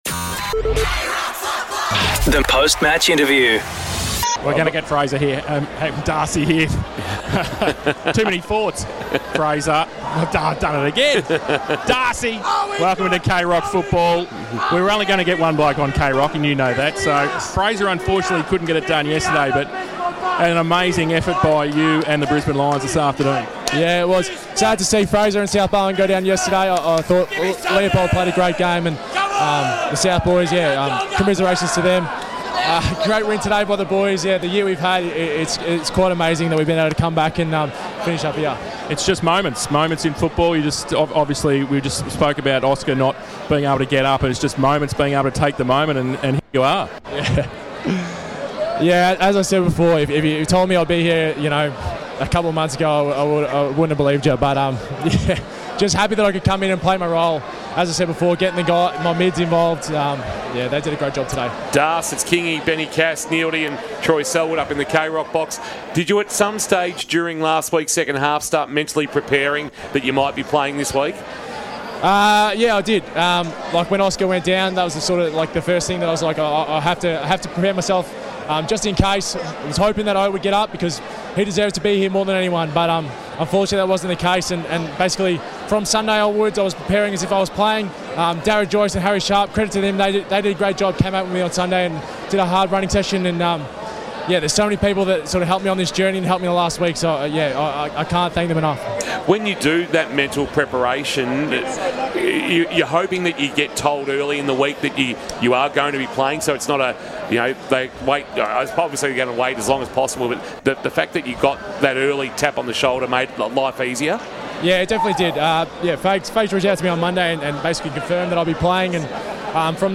2024 - AFL - Grand Final - Sydney vs. Brisbane: Post-match interview - Darcy Fort (Brisbane Lions)